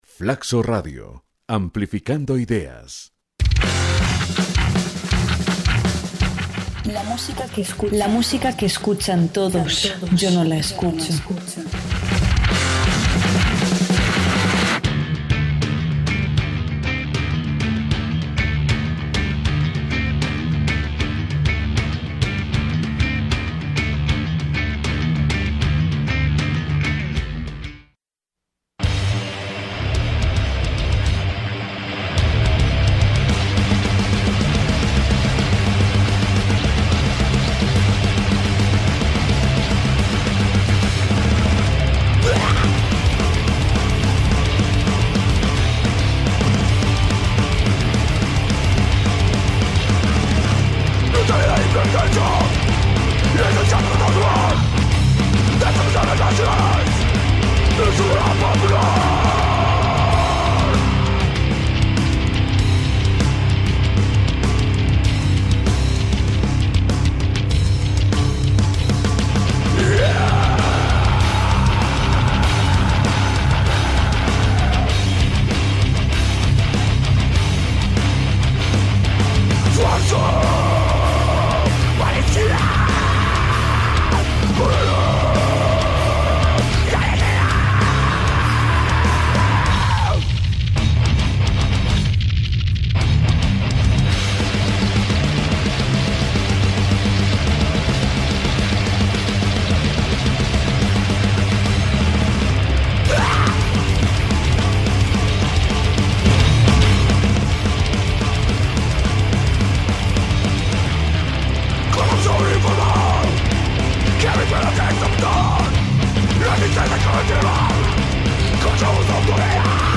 Escucharemos canciones de algunas de las bandas que participarán de esta edición del Quitofest.